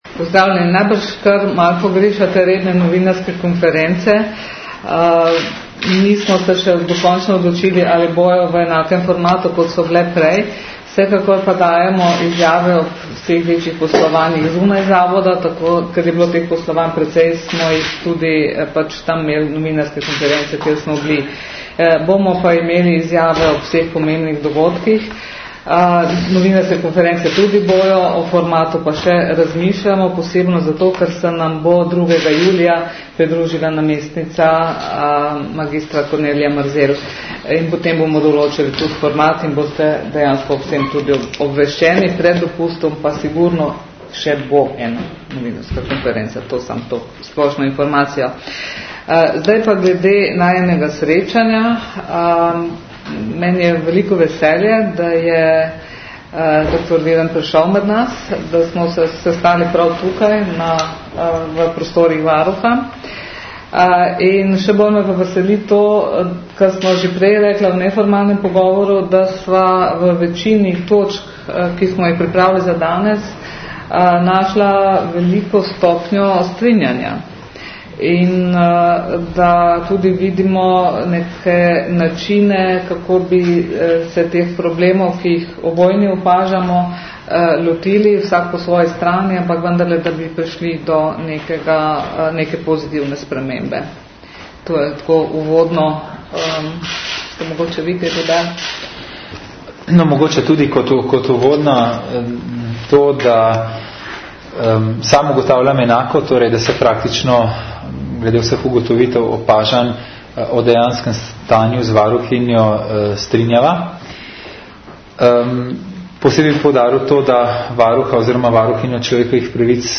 Varuhinja na delovni obisk sprejela ministra Viranta - zvočni posnetek skupne izjave
Varuhinja človekovih pravic dr. Zdenka Čebašek-Travnik je na delovni obisk sprejela ministra za javno upravo dr. Gregorja Viranta. Po srečanju sta javnost seznanila z vsebino pogovora.